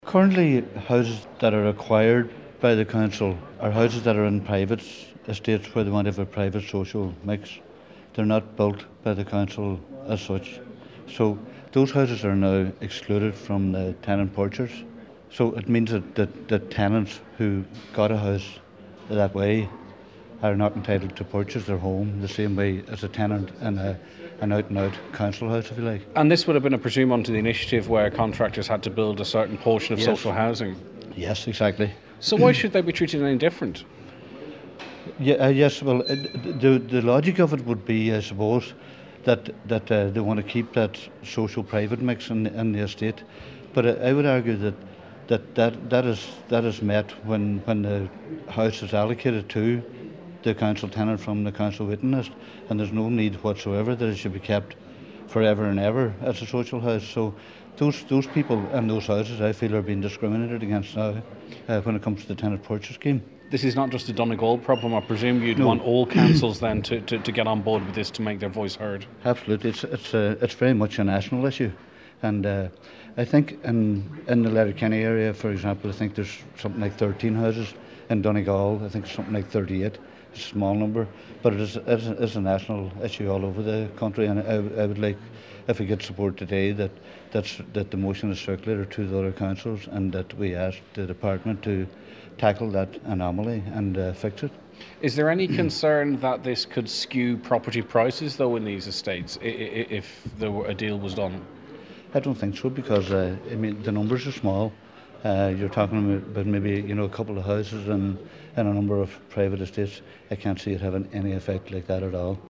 Councillor Jimmy Kavanagh says this is a country wide issue that must be addressed…………